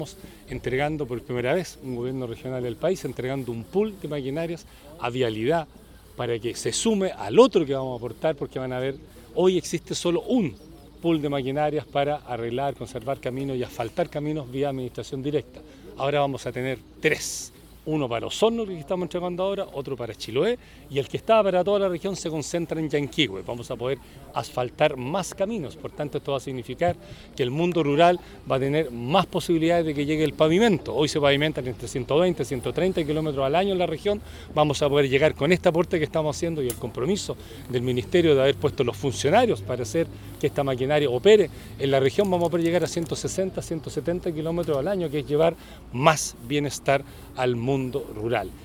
Por su parte, el Gobernador Regional Patricio Vallespin, señaló que ahora el mundo rural podrá tener la posibilidad de que sus caminos sean pavimentados, con un estimado cercano a los 170 kilómetros por año.